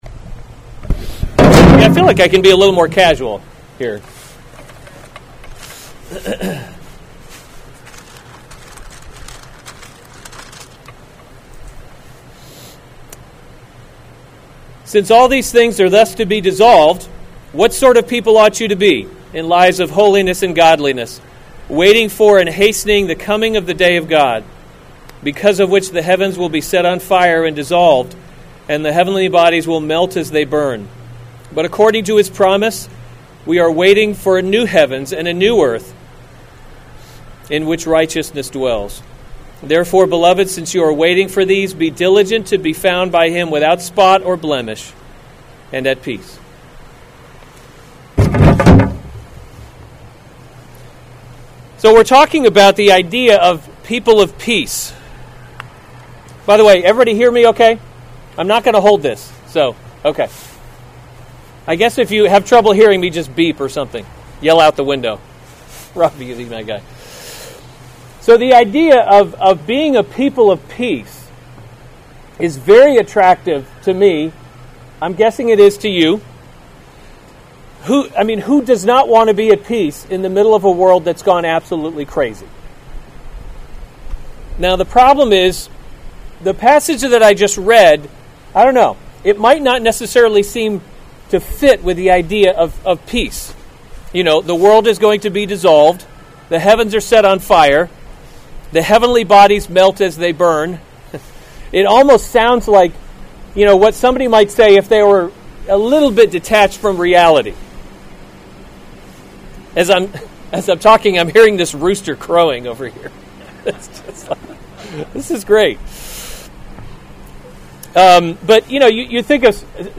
February 6, 2021 2 Peter – Covenant Living series Weekly Sunday Service Save/Download this sermon 2 Peter 3:11-14 Other sermons from 2 Peter 11 Since all these things are thus to […]